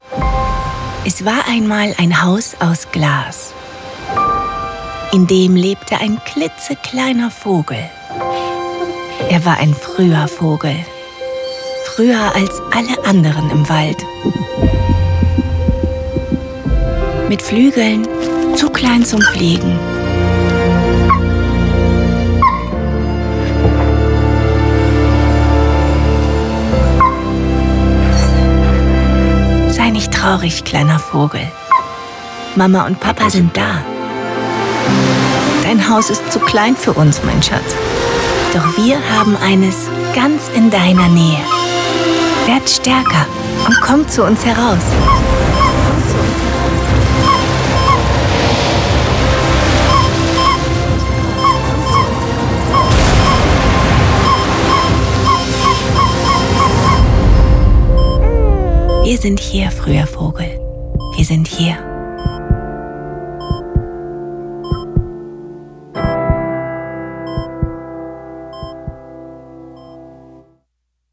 sehr variabel, markant
Selbstbewusst, natürlich, jung - Nivea Sun 2024
Commercial (Werbung)